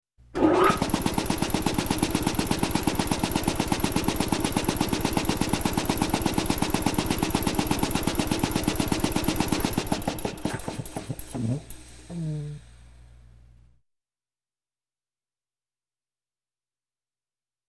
Compressor
Motorized Shop Tool, Compressor Motor 4; Turn On, Steady Chugging Motor, And Off With Wind Down And Toned Release.